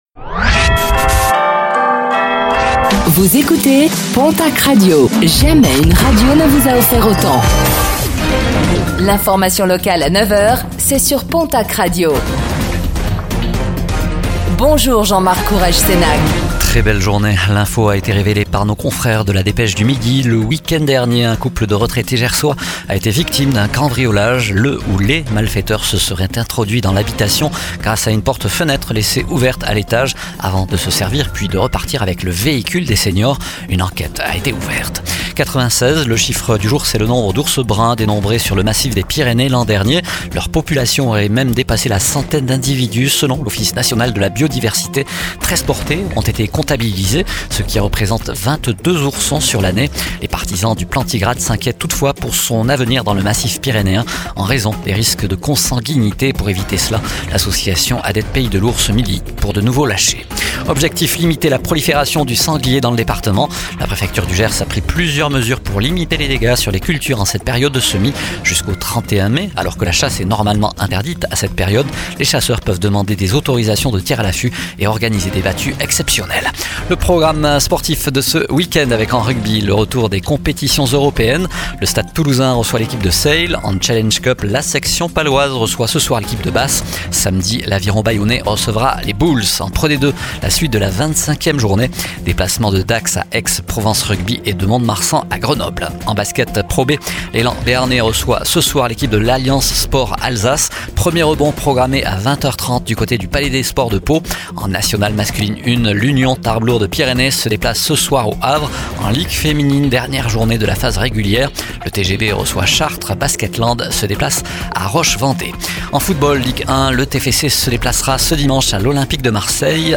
09:05 Écouter le podcast Télécharger le podcast Réécoutez le flash d'information locale de ce vendredi 04 avril 2025